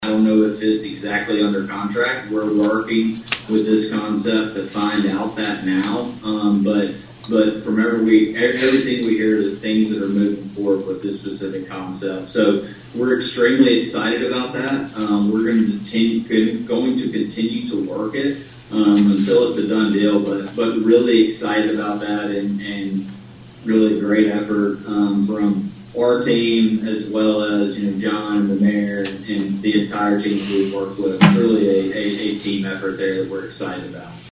attended the Atlantic City Council meeting via Zoom on Wednesday. He highlighted four prospects interested in coming to Atlantic.